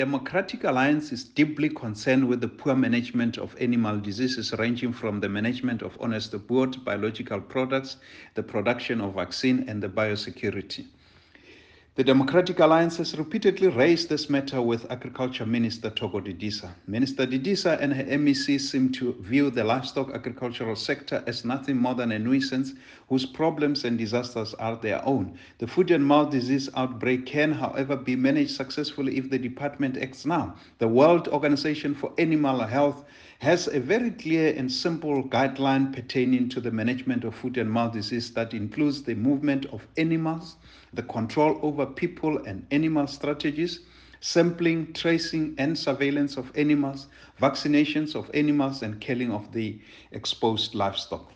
soundbite by Noko Masipa MP.